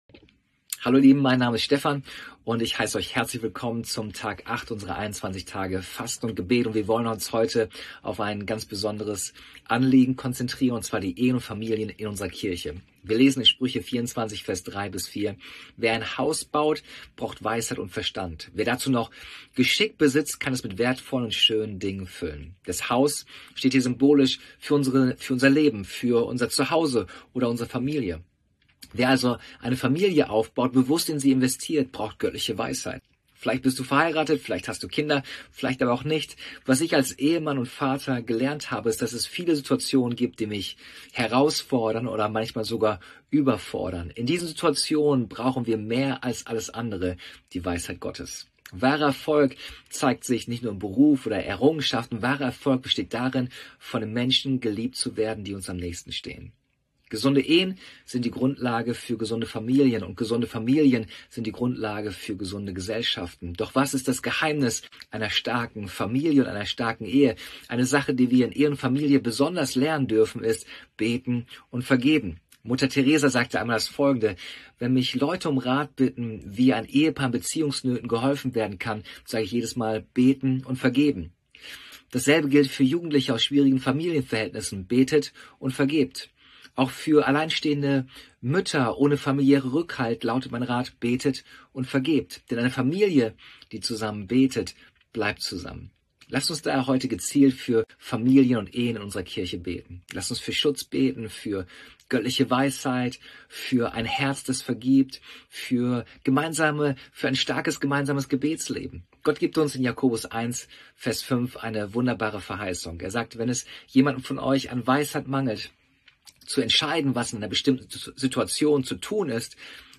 Andacht zu unseren 21 Tagen des Gebets